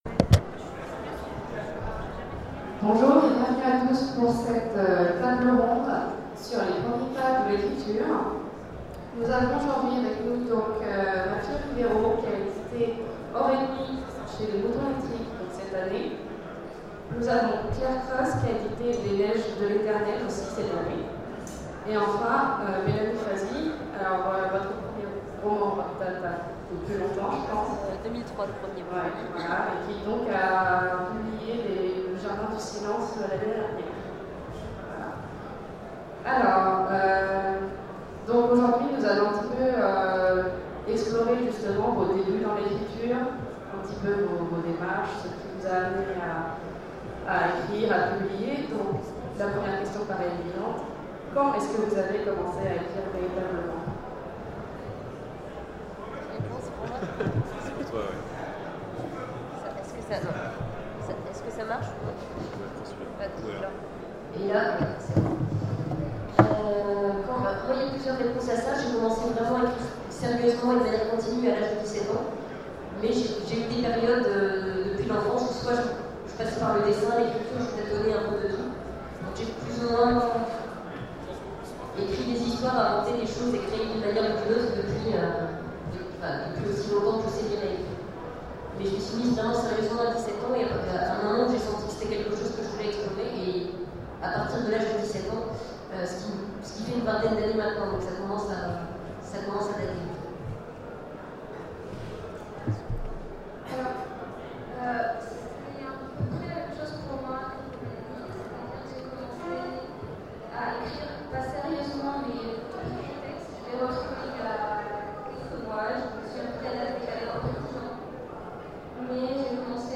Conférences Octogones 2015 : Premiers pas de l'écriture
Conférence